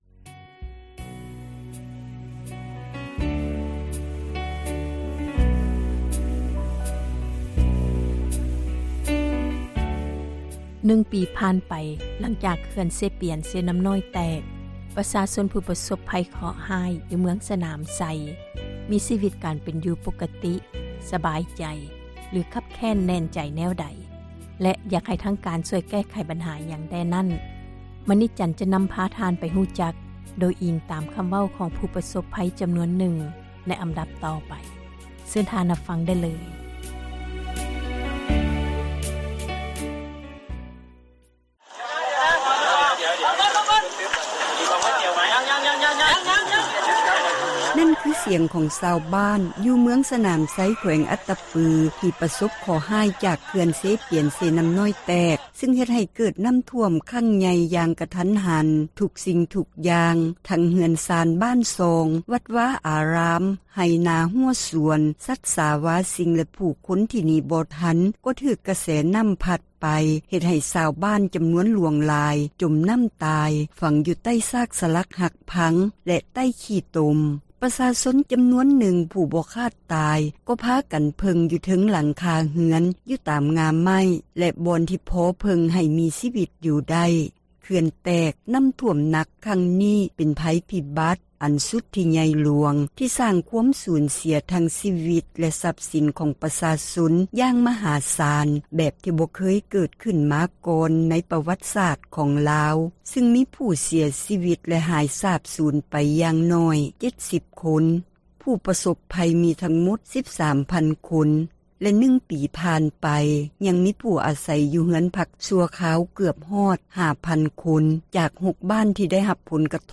ນຶ່ງປີຜ່ານໄປ ພາຍຫລັງ ເຂື່ອນ ເຊປຽນ-ເຊນໍ້ານ້ອຍ ແຕກ ປະຊາຊົນ ຜູ້ເຄາະຮ້າຍ ຢູ່ ເມືອງສນາມໄຊ ມີຊີວິດ ການເປັນຢູ່ ປົກກະຕິ ສະບາຍໃຈ ຫລື ຄັບແຄ້ນໃຈ ແນວໃດ ມາຟັງ ຄໍາເວົ້າຂອງ ຜູ້ປະສົບພັຍ ຈໍານວນນຶ່ງ.